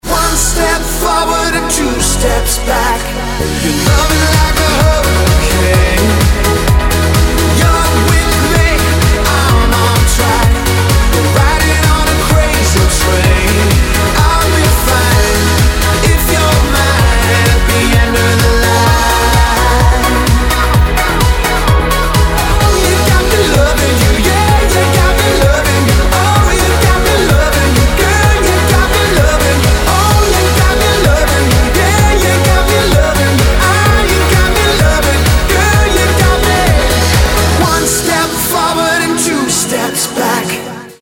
• Качество: 192, Stereo
Крутой ремикс на песню популярного бойз-бенда!